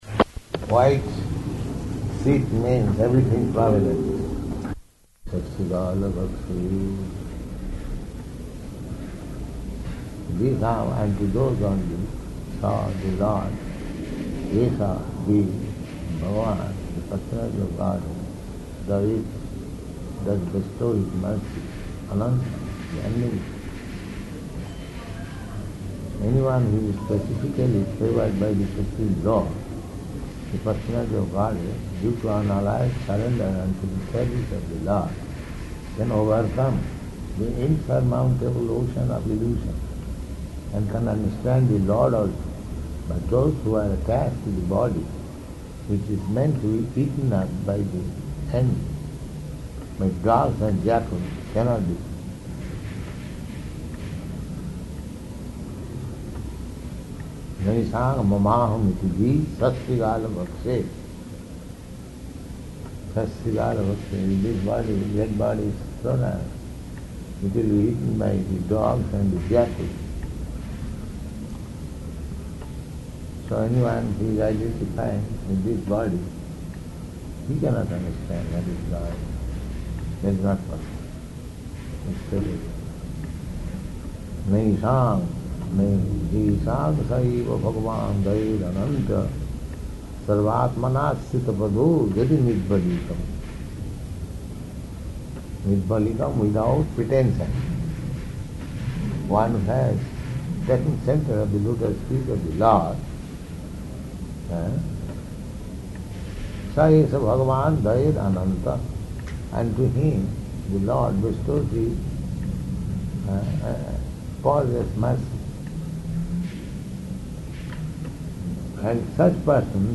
Room Conversation on Śrīmad-Bhāgavatam 2.7.42
Room Conversation on Śrīmad-Bhāgavatam 2.7.42 --:-- --:-- Type: Conversation Dated: July 1st 1971 Location: Los Angeles Audio file: 710701R2-LOS_ANGELES.mp3 Prabhupāda: White sheet means everything prominent.